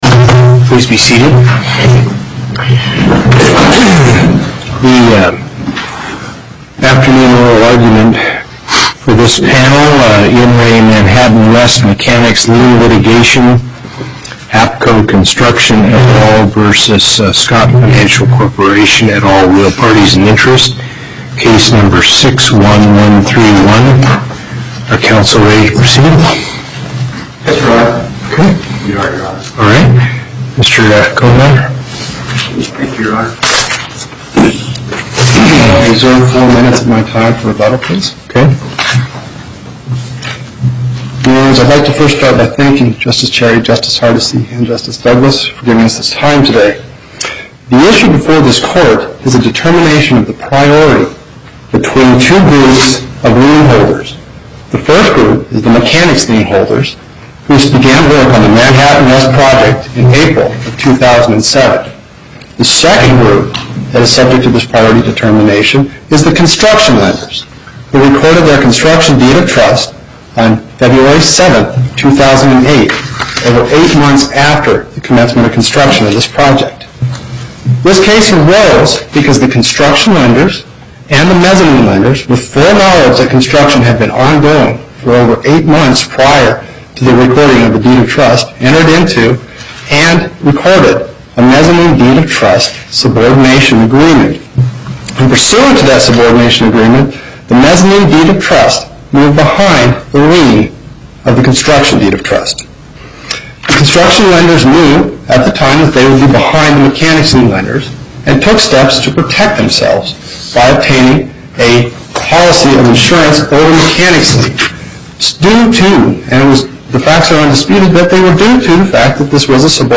1:30 P.M. Location: Las Vegas Before the Southern Nevada Panel, Justice Hardesty Presiding Appearances